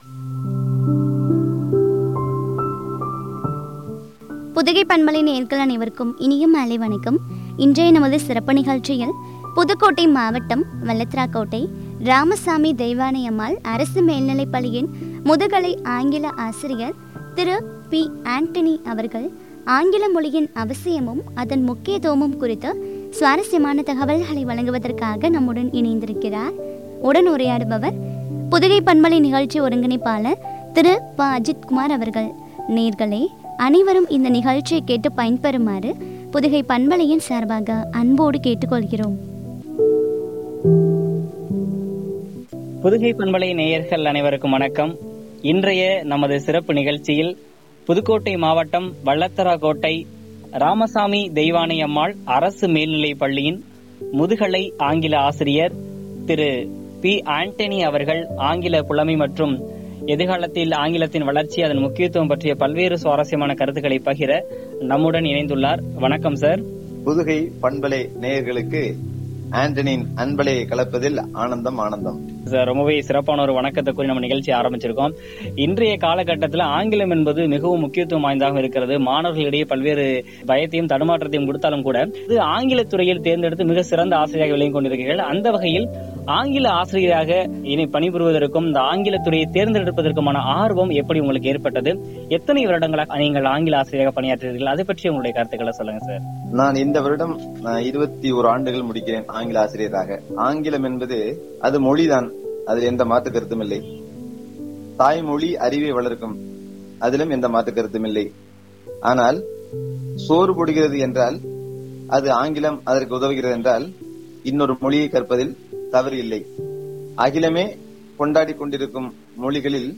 முக்கியத்துவமும் பற்றி வழங்கிய உரையாடல்.